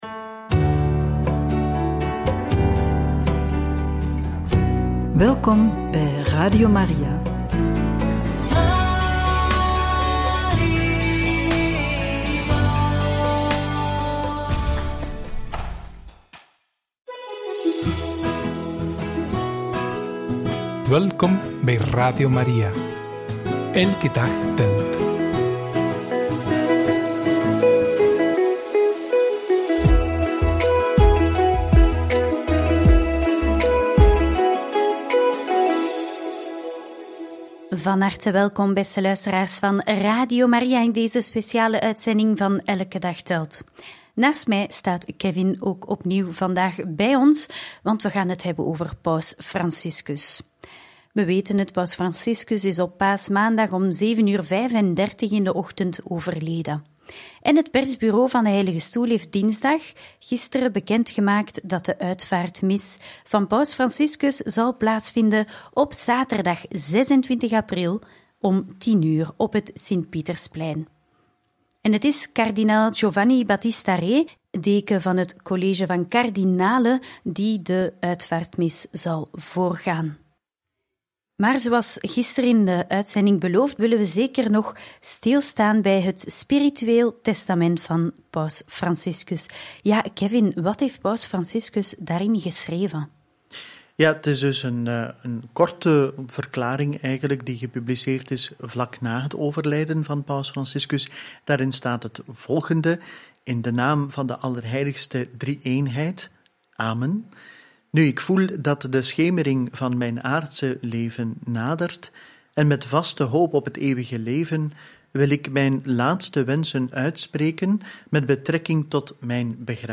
In Memoriam: We overlopen de jaren 2016-2019 van het pontificaat – In gesprek met kardinaal De Kesel over Paus Franciscus – Radio Maria